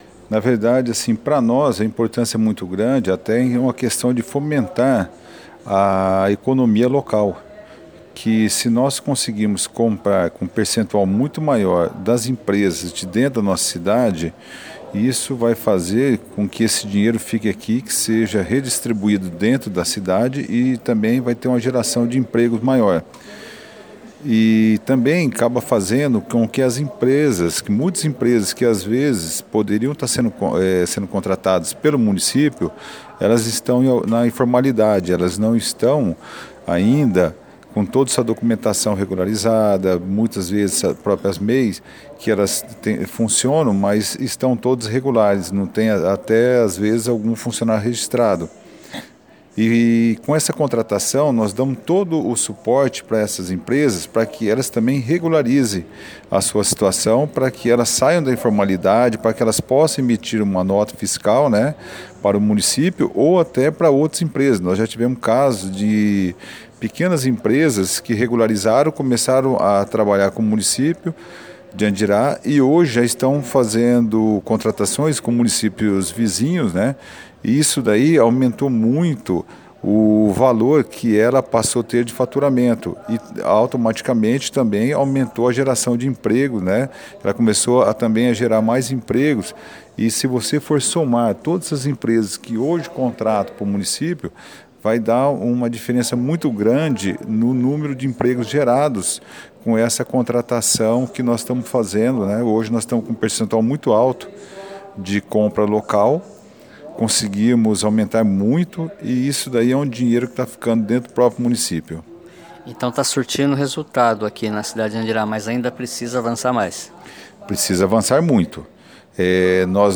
O Seminário Regional de Compras Públicas, realizado no município de Andirá, na última quarta-feira (7), reuniu representantes dos setores administrativos (em especial a área de compras) de dezessete prefeituras do Norte Pioneiro.